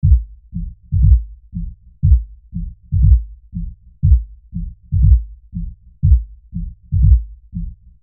Here I simply applied a low pass filter until all that could be heard was the kick drum in the loop.
The low pass filter is isolated to help identify the correct area